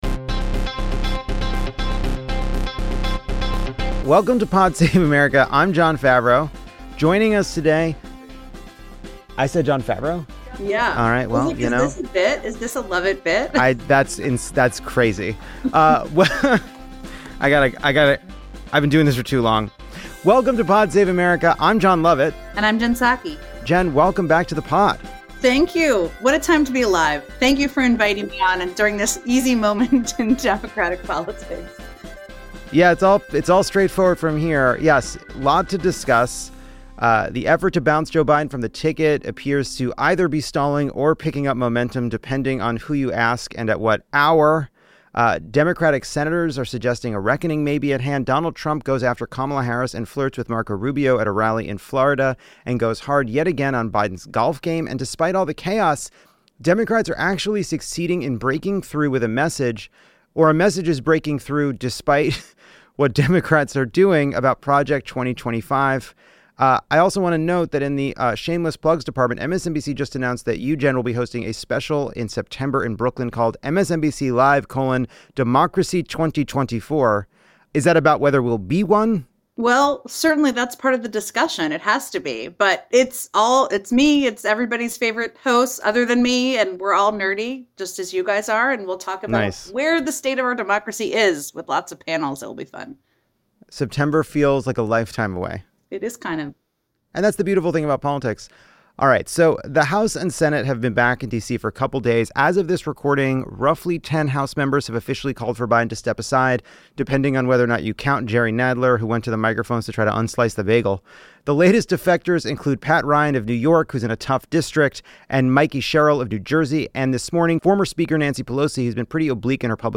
MSNBC's Jen Psaki joins Lovett to break down the latest, and to talk about openly about what Biden needs to do to hang on.